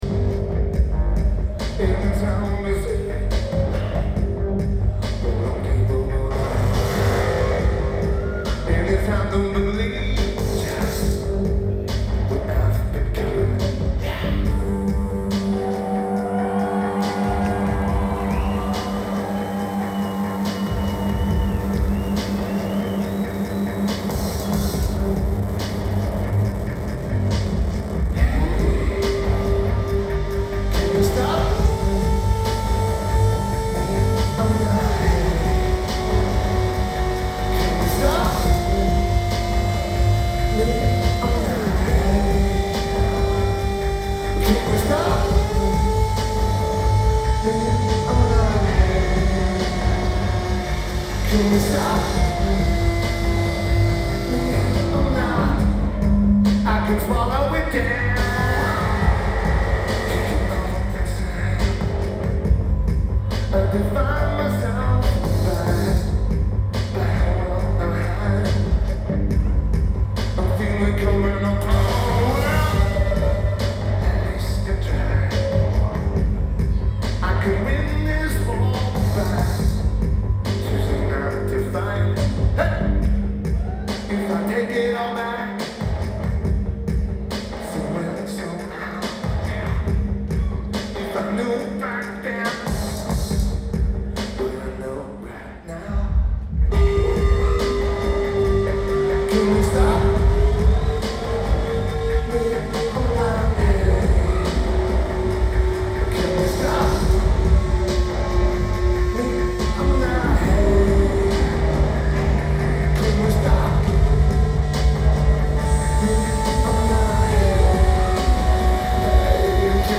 The Wiltern Theatre
Los Angeles, CA United States
Drums
Bass
Guitar
Vocals/Guitar/Keyboards
Lineage: Audio - AUD (DPA 4061 + Sony PCM-D50)